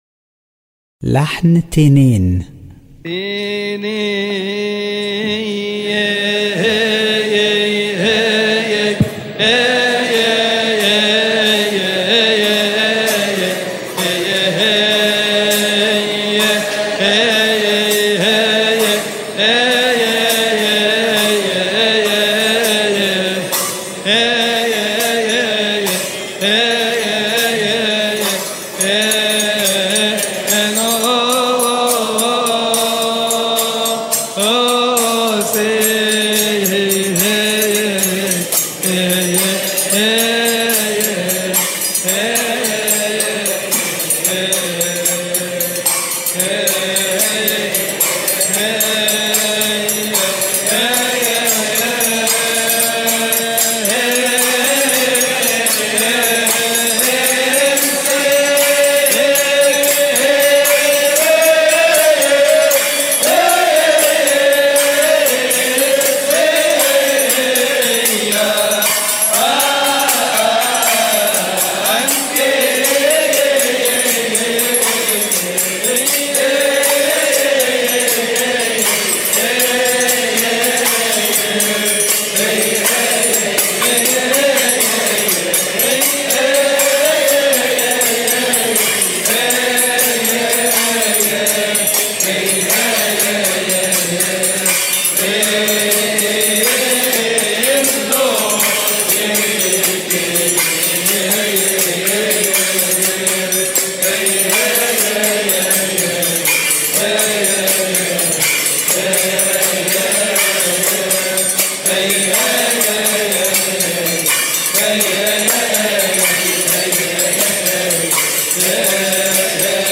استماع وتحميل لحن لحن تينين من مناسبة keahk